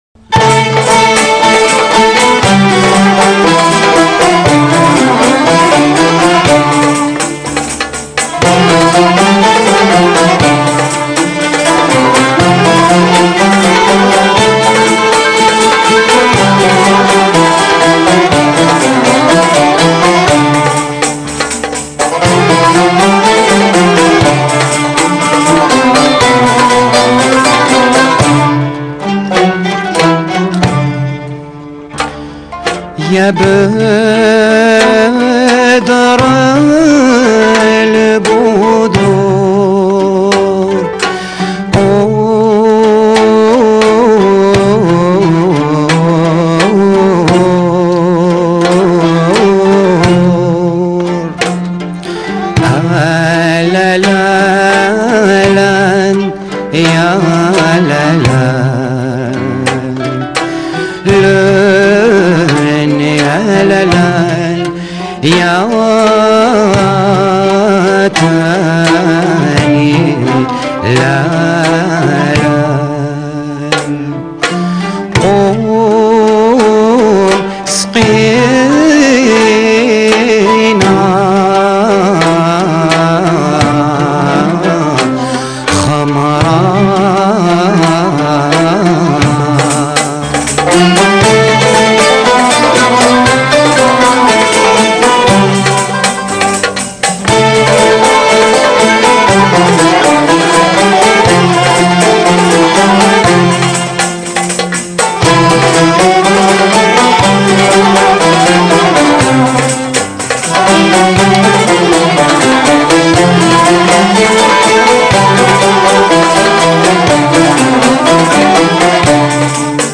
GHARNATI_3.mp3